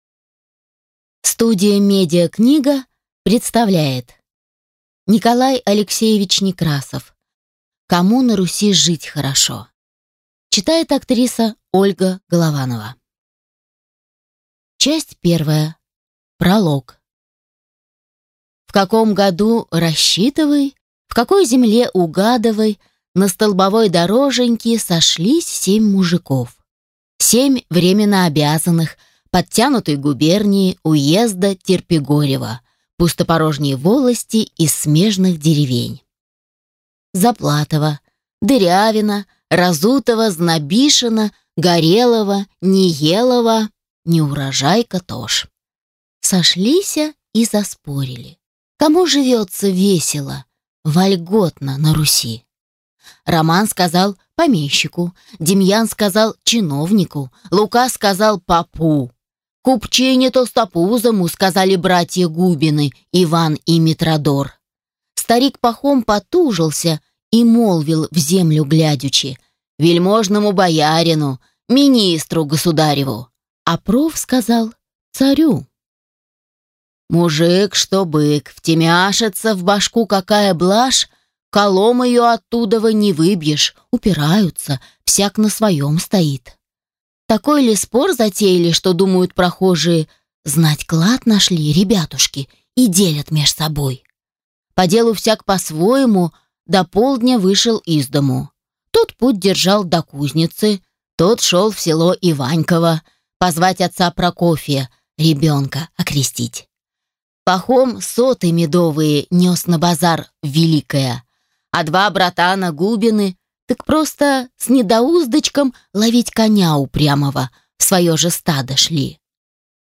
Аудиокнига Кому на Руси жить хорошо | Библиотека аудиокниг